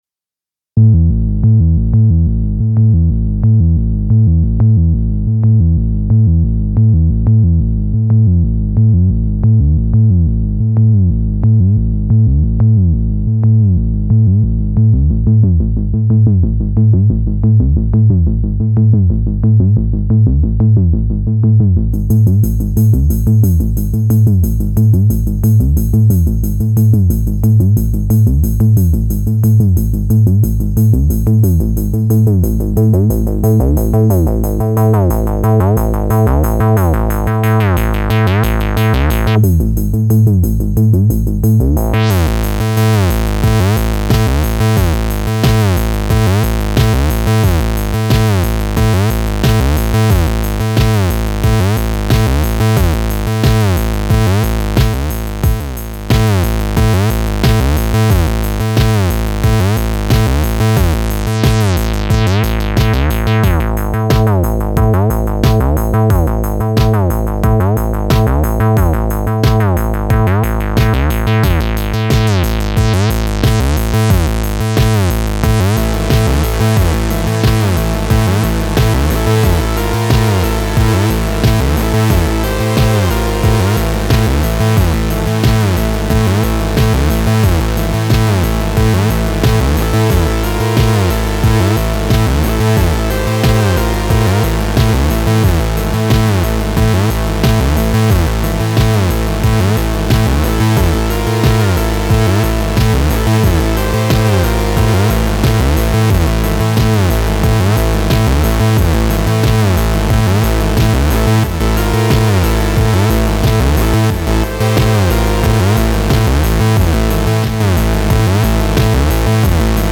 Its not going to pass an A/B test but to my sensibilities it sounds like a good start, would be a matter of finetuning further with references from here to get it even closer…
909CHH#2.syx